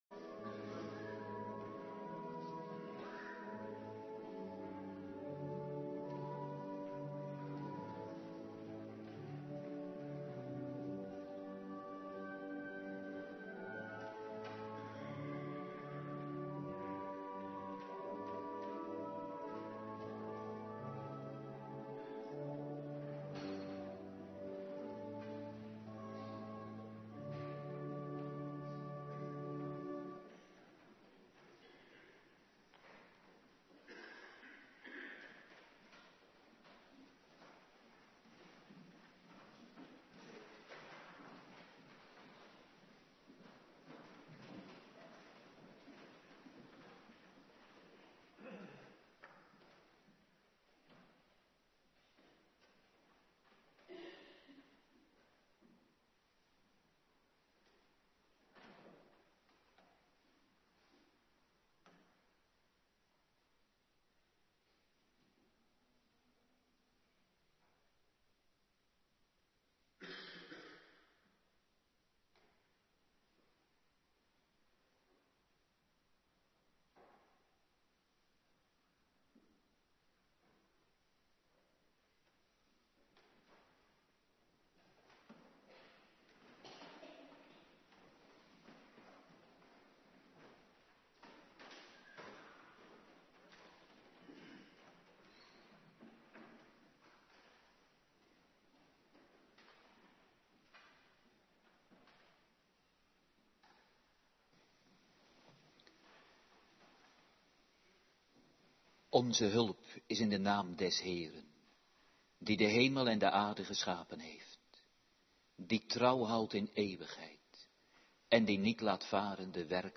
Avonddienst Bijbellezing
19:30 t/m 21:00 Locatie: Hervormde Gemeente Waarder Agenda: Kerkdiensten Terugluisteren Bijbellezing Romeinen 6:15-23